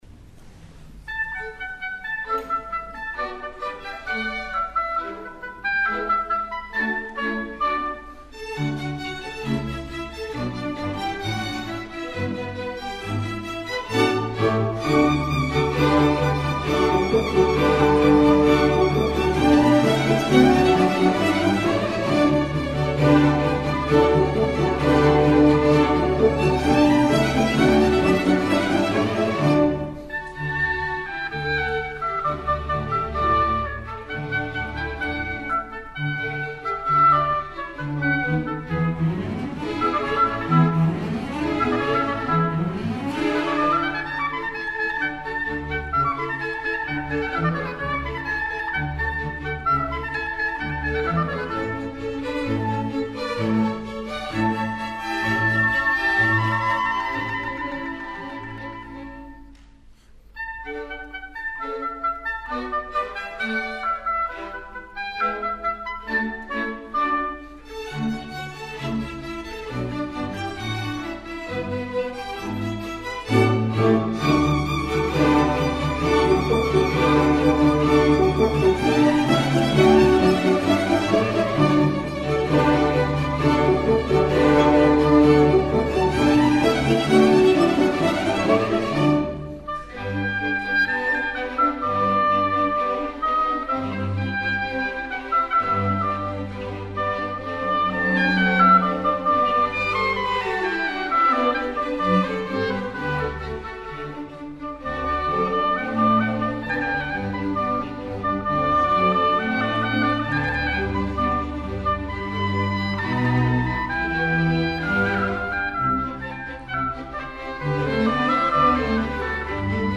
Sinfonischer Musikverein Leipzig e. V. – ein Liebhaberorchester in Leipzig
Ludwig August Lebrun: Konzert Nr. 1  d-moll  für Oboe und Orchester
Lebrun_Konz_Oboe_Orch_d_moll_satz3.MP3